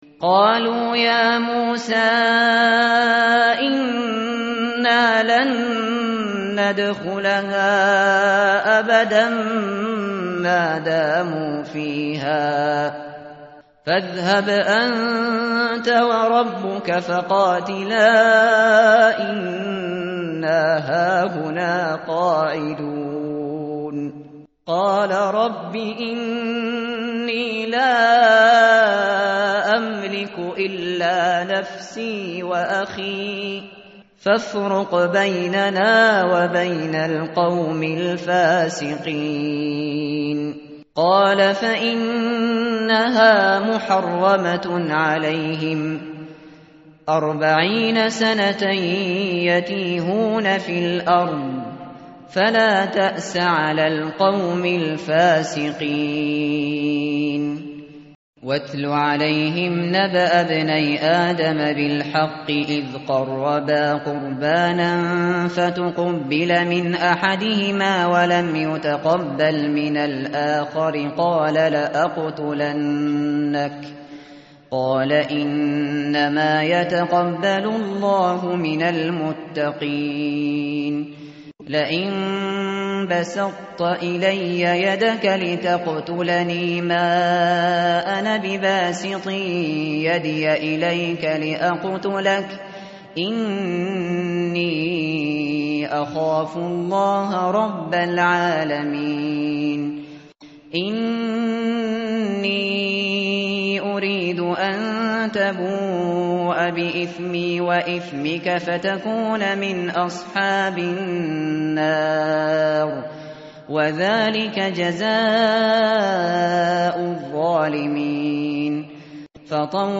متن قرآن همراه باتلاوت قرآن و ترجمه
tartil_shateri_page_112.mp3